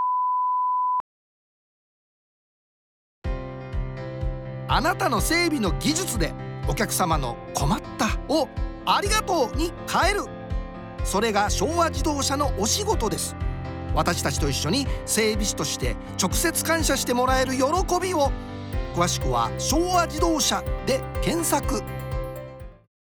ラジオCM制作（リクルート版）